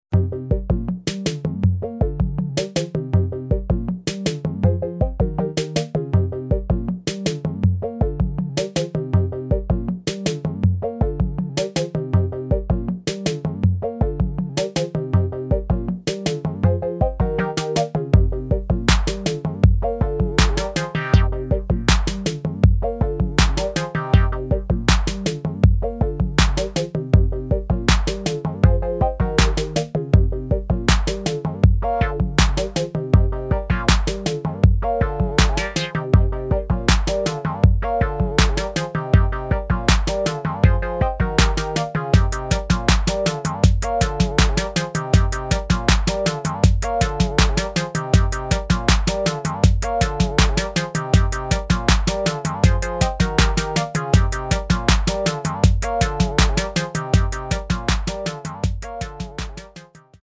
[ TECHNO | ELECTRO | SOCA ]